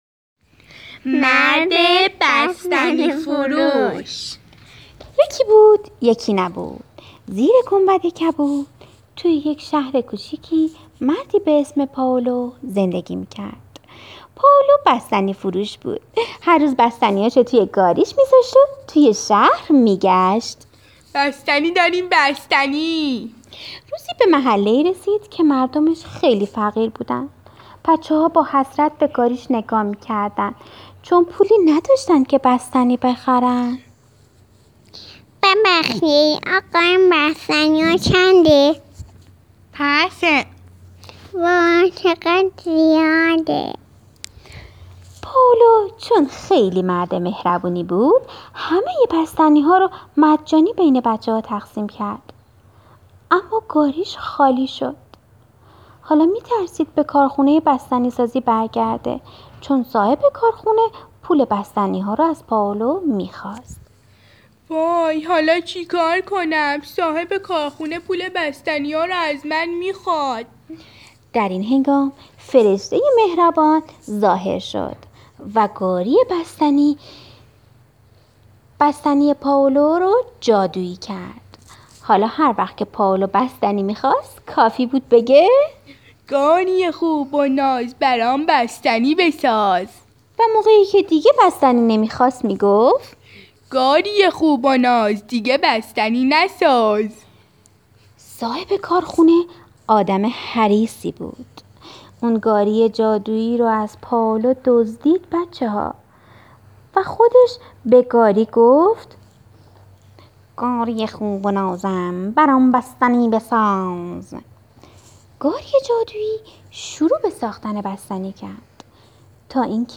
مرد بستنی فروش - رادیو قصه صوتی کودکانه